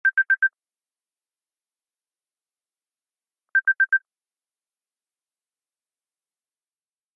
macOSsystemsounds